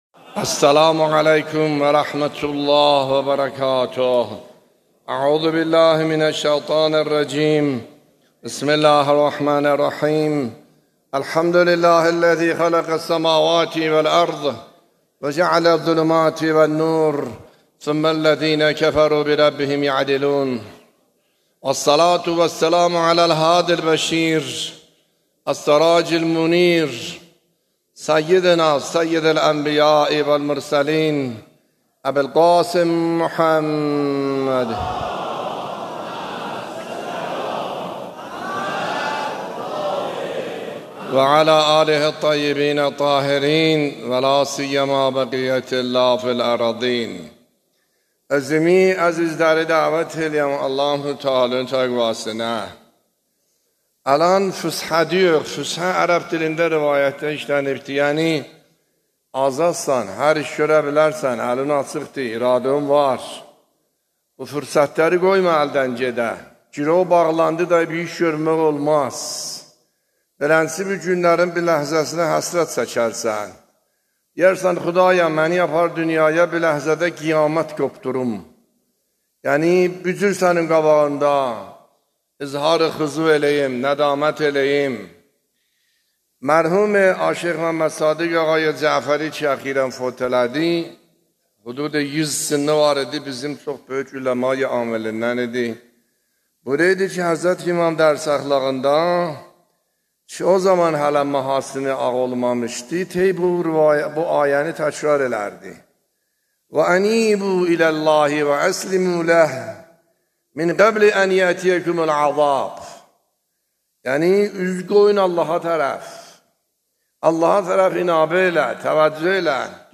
خطبه‌ های نماز جمعه اردبیل | آیت الله عاملی (30 تیر 1402) + متن | ضیاءالصالحین